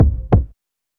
ノック